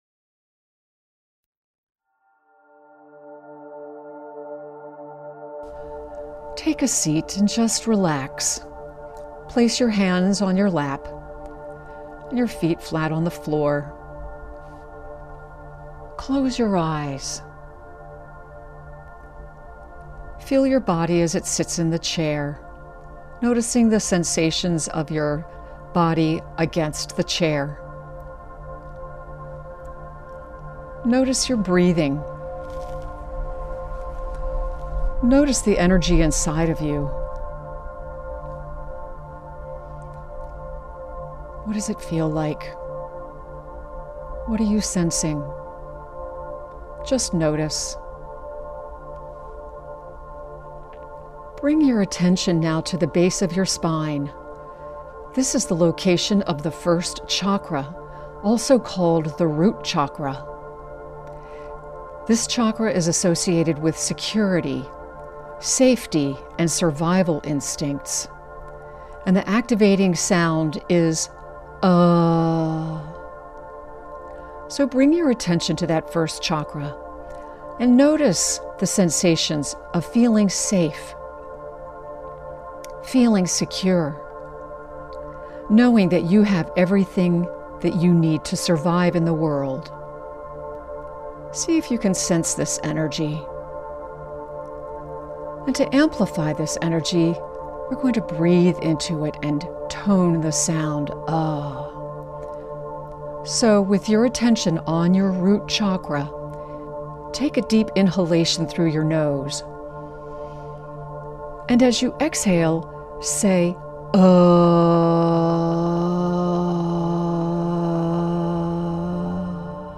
Energizing Creative Expression Practice with Meditation Recording